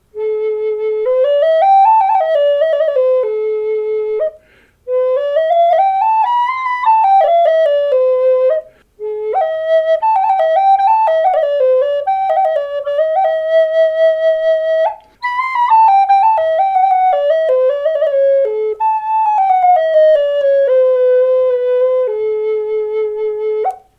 A4 branch flute in Sycamore (has both above octave notes, now; new sample) DONATED TO FEATHERHEADS